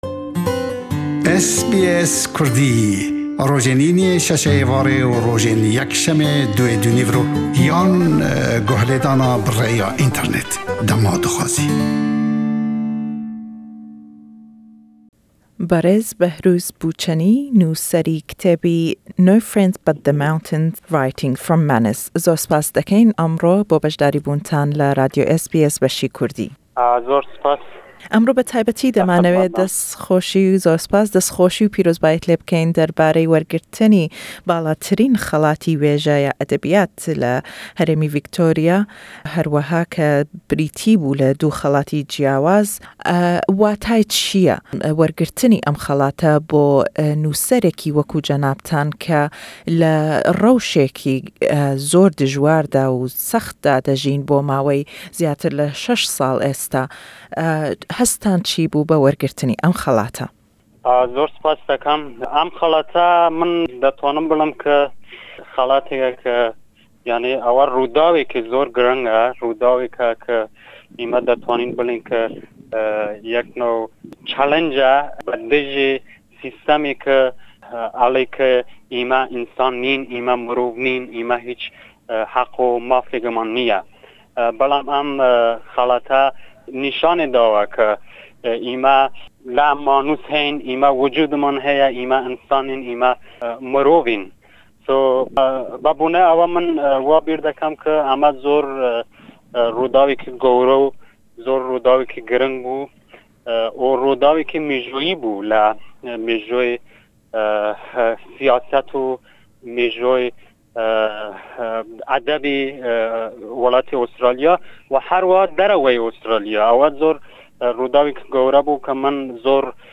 Kurdish Journalist and author Behrouz Boochani speaks to us about winning top literary award for his book No Friend but the Mountains: Writing from Manus Prison, which also won the category for best non-fiction book prize.